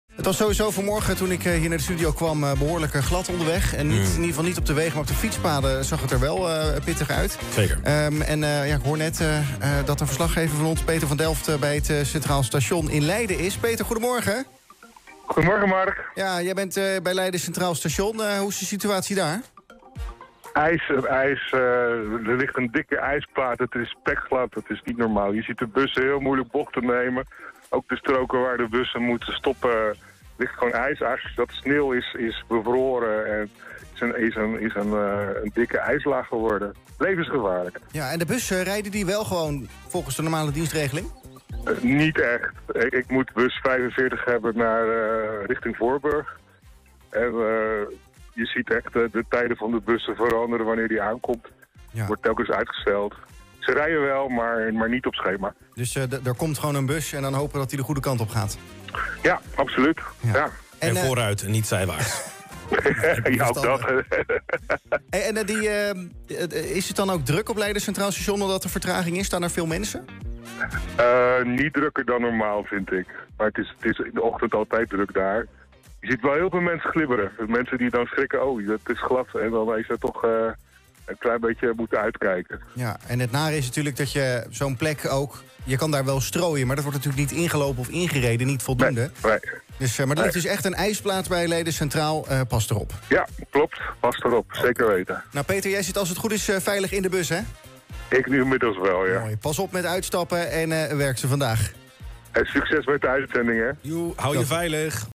Presentator